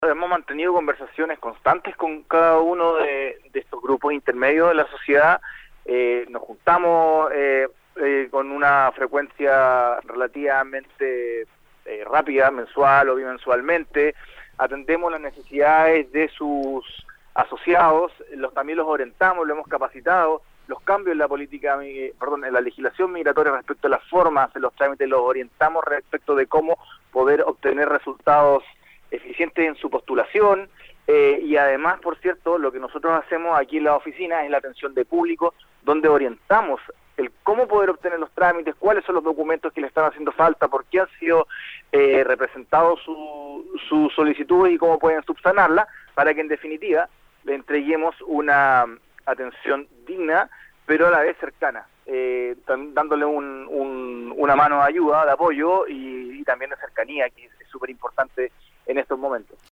En entrevista con Nuestra Pauta, el director regional del Servicio Nacional de Migraciones, Sebastián Maldonado, afirmó que se trata principalmente de personas que «buscan establecerse, una segunda oportunidad. Que quieren aportar a la comunidad y a la Región del Biobío y en eso estamos trabajando».